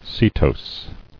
[se·tose]